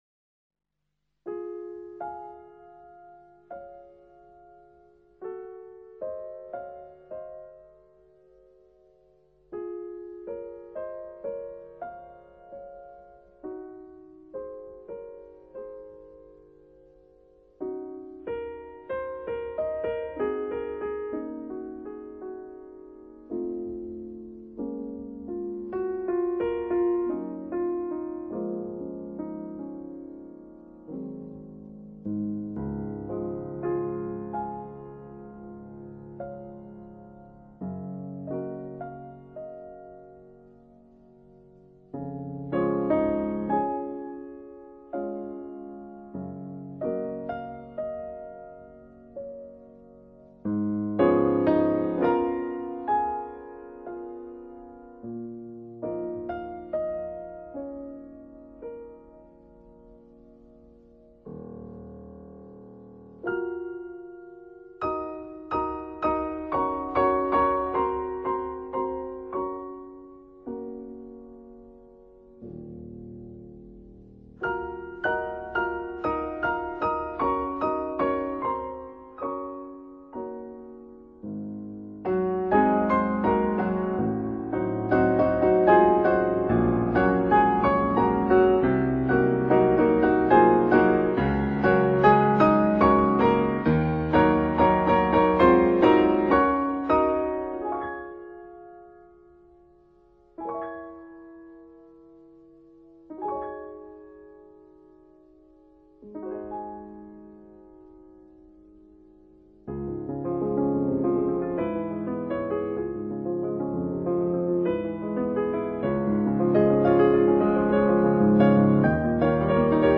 classical piano album